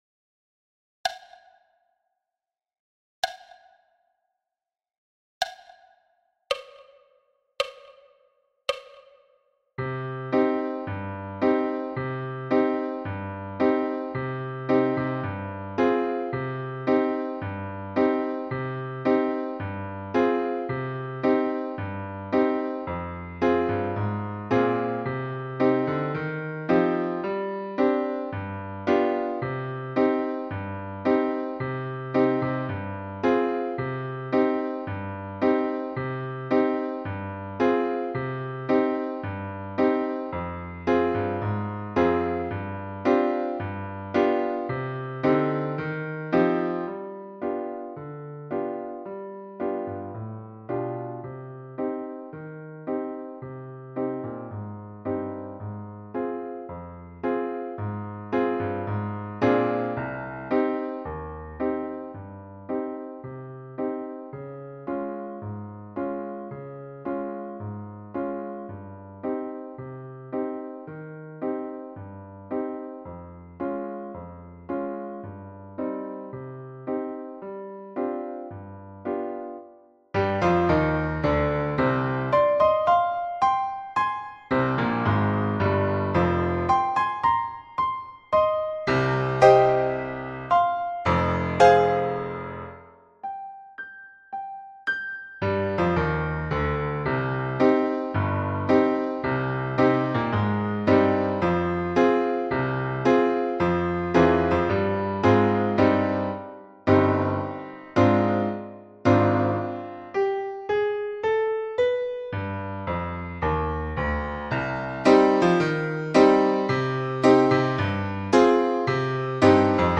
Midi pile – piano à 55 bpm-Part
Midi-pile-piano-a-55-bpm-Part.mp3